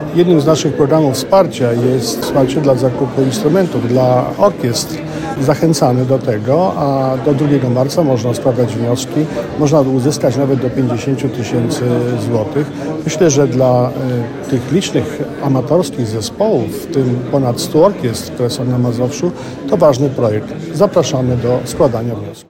– przekazał marszałek Adam Struzik.